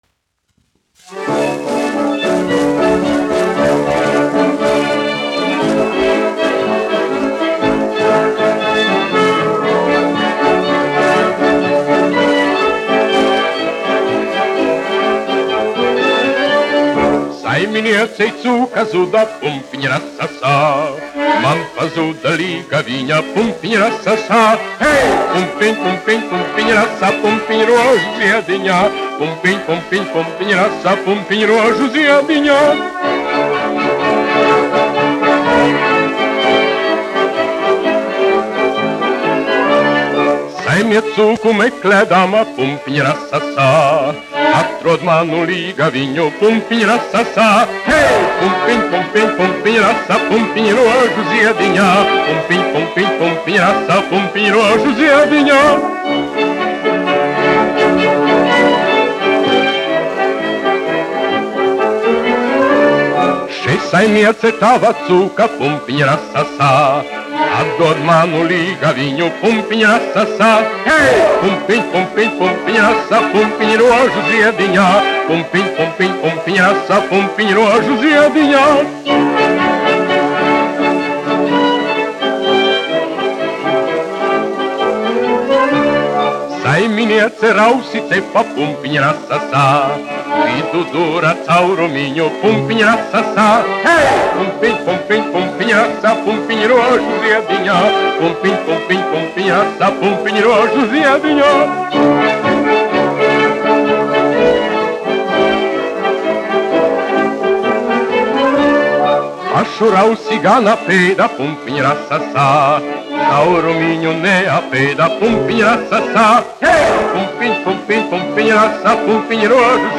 1 skpl. : analogs, 78 apgr/min, mono ; 25 cm
Polkas
Populārā mūzika
Latvijas vēsturiskie šellaka skaņuplašu ieraksti (Kolekcija)